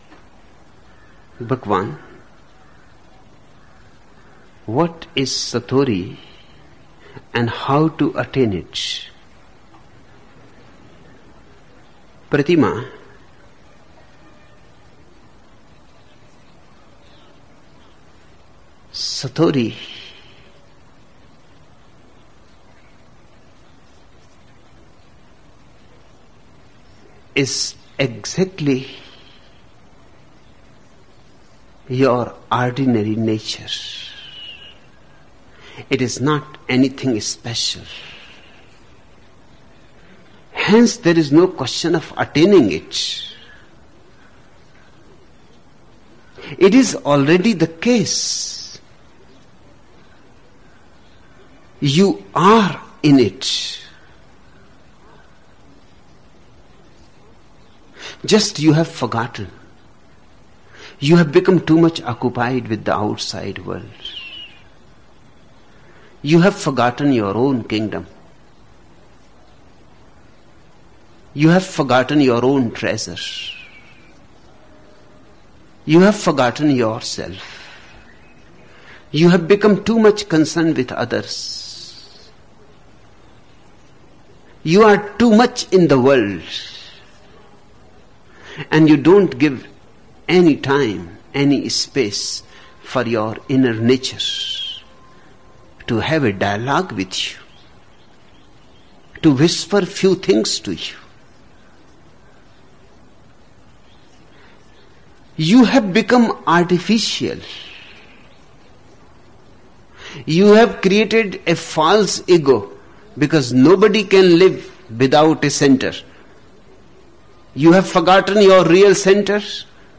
Each program has two parts, Listening Meditation (Osho discourse) and Satsang Meditation.
The Osho discourses in the listening meditations in this module are from discourses in which Osho spoke on Zen in various discourse series in Pune, India from 1974 to 1989.